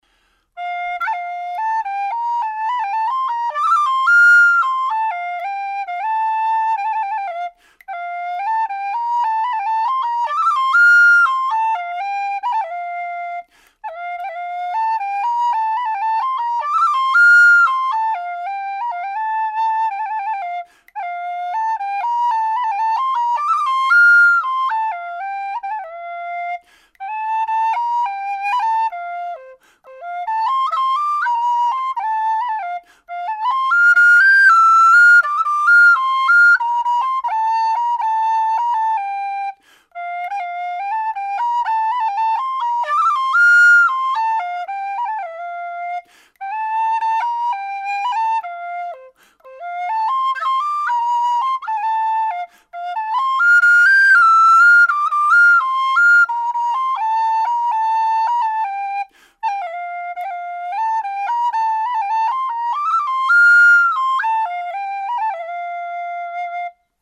C whistle
made out of thin-walled aluminium tubing with 14mm bore